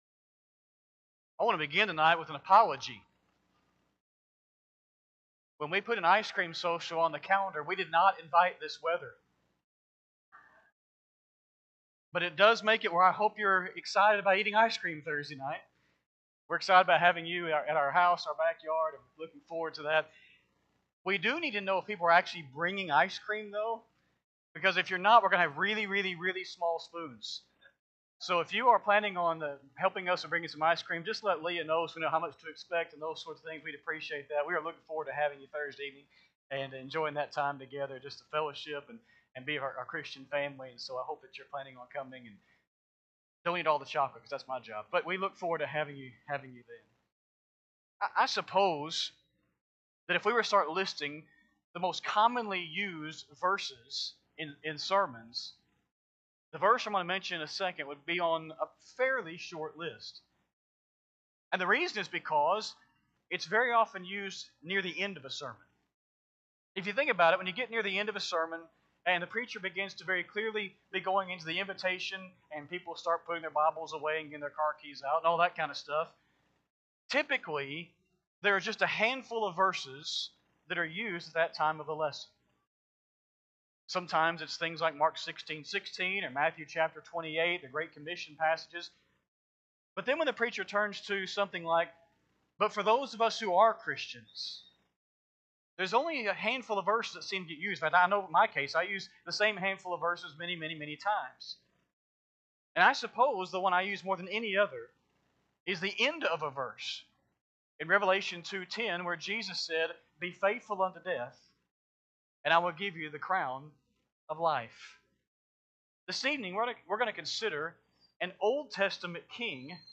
6-22-25-Sunday-PM-Sermon.mp3